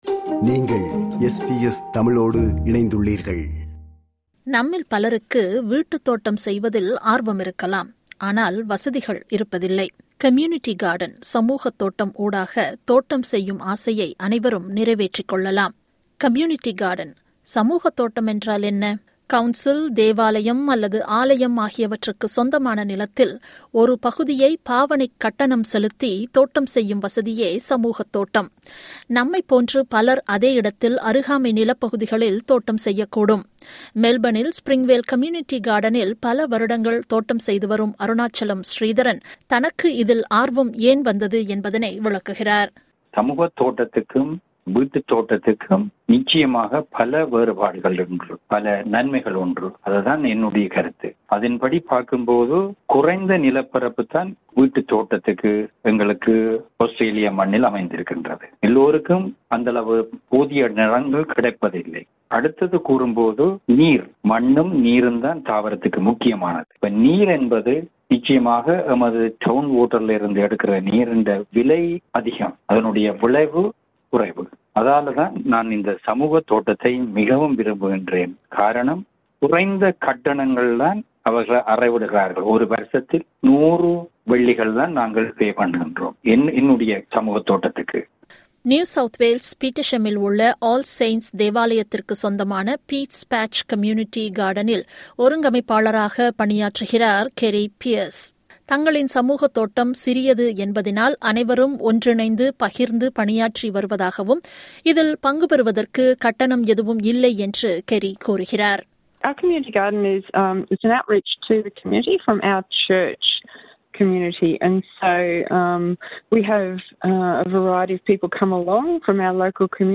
இது போன்ற பல கேள்விகளுக்கு விடையளிக்கிறது இந்த விவரணம்.